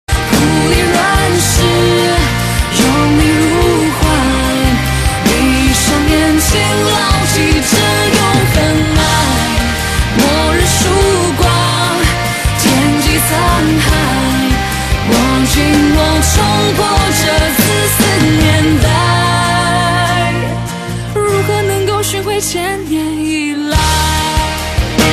M4R铃声, MP3铃声, 华语歌曲 85 首发日期：2018-05-15 07:23 星期二